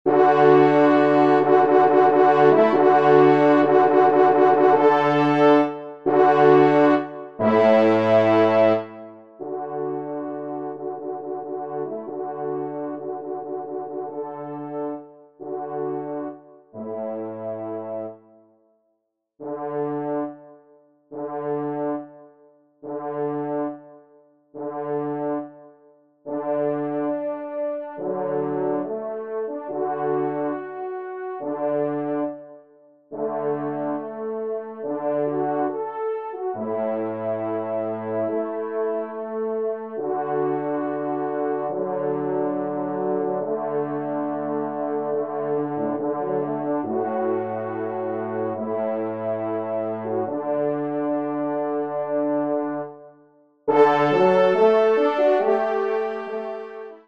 Genre :  Divertissement pour Trompes ou Cors en Ré
4e Trompe